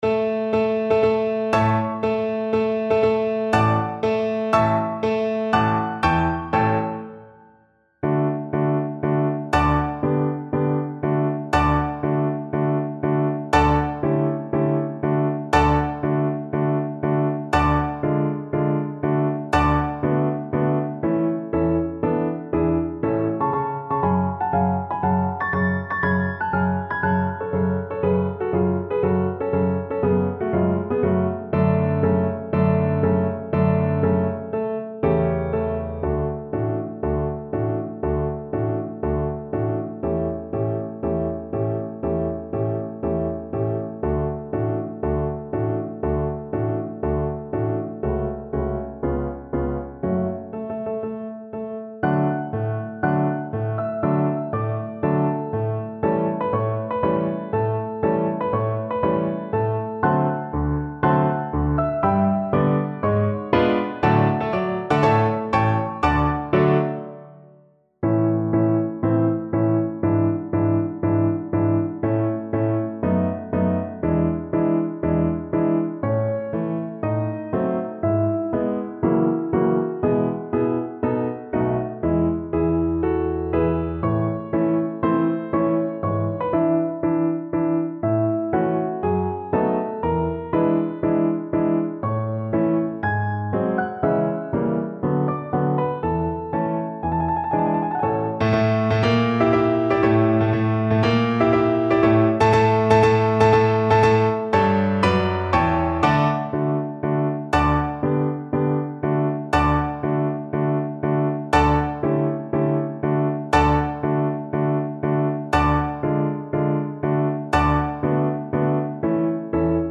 Violin version
Allegro marziale (View more music marked Allegro)
4/4 (View more 4/4 Music)
Classical (View more Classical Violin Music)